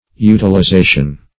Utilization \U`til*i*za"tion\, n. [Cf. F. utilization.]